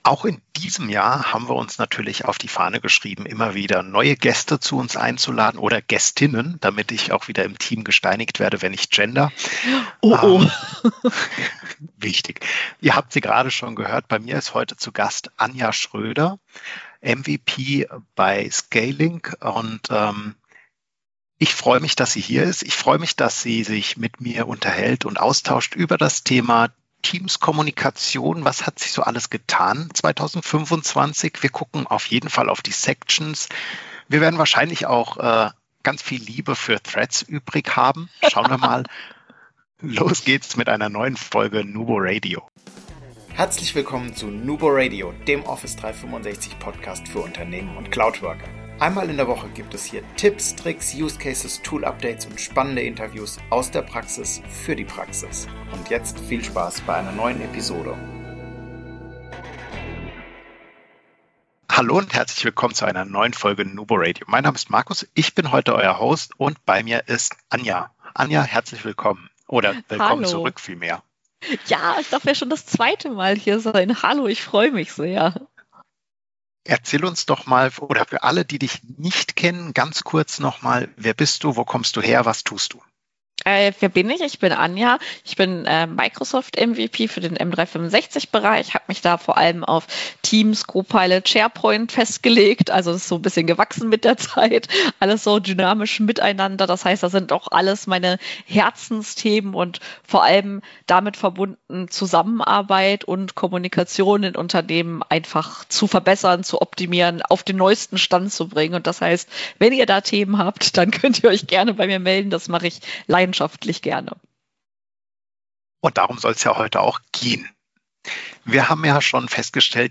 Was passiert, wenn zwei Teams-Profis offen über die neuesten Entwicklungen in Microsoft Teams sprechen?